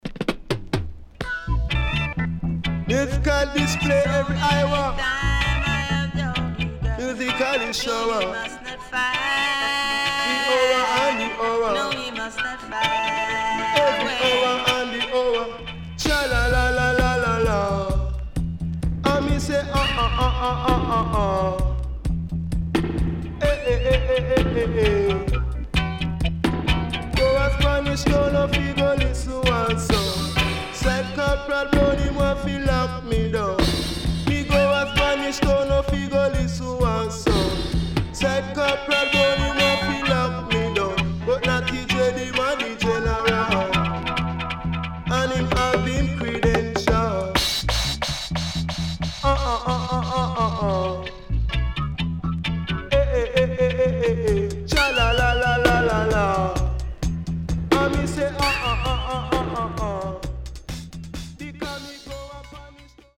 HOME > LP [VINTAGE]  >  70’s DEEJAY
OLD SCHOOL Deejay